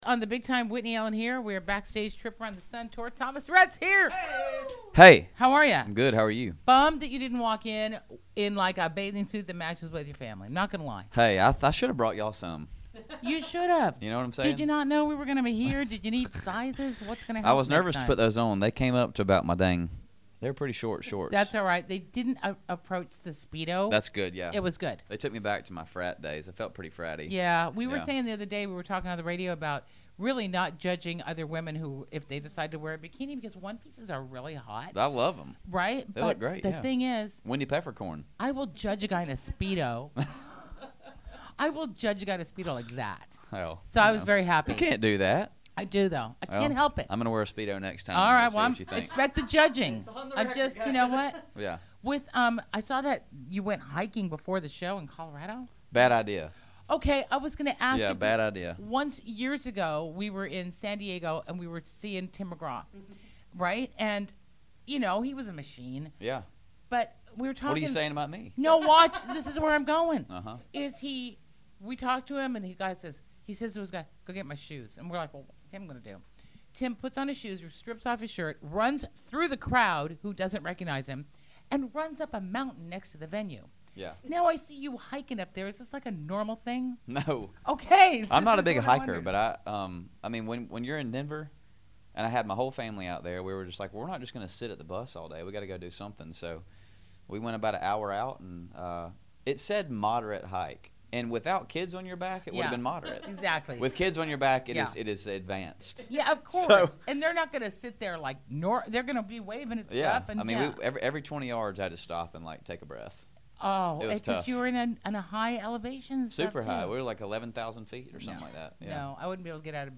Backstage Broadcast At Trip Around The Sun Tour: Thomas Rhett